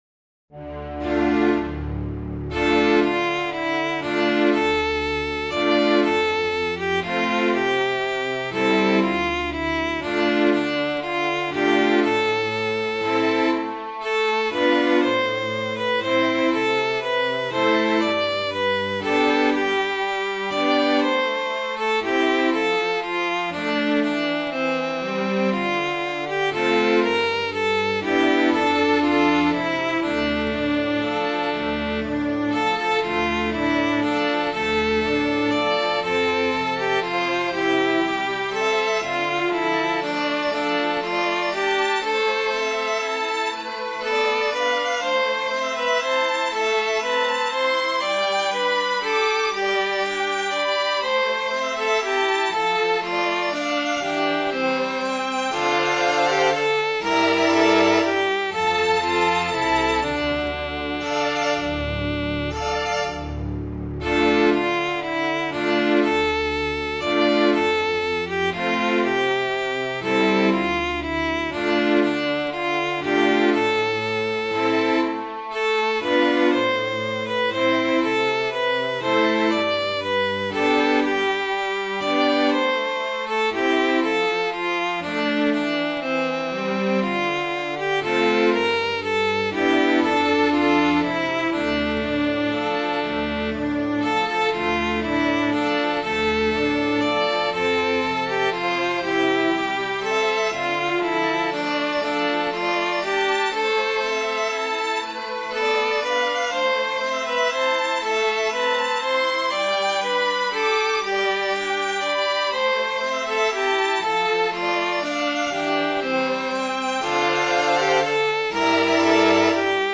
Midi File, Lyrics and Information to Robin Hood and the Tanner